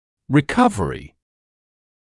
[rɪ’kʌvərɪ][ри’кавэри]восстановление; выздоровление